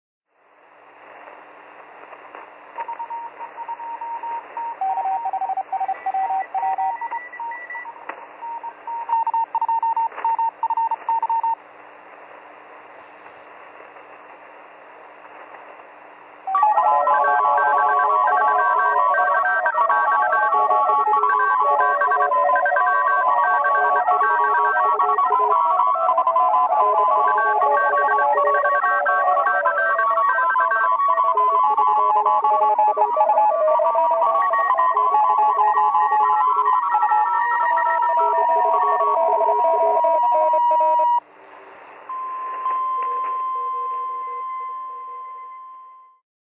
Well, I was tunning around 7240 khz tonight to try my hand at copying some morse code from the ether. Well, I experienced what a pile sounds like - for real - for the first time.
Whatever the reason… a few dozen people try to answer the same call to the same station at the same time.
morse_pileup.mp3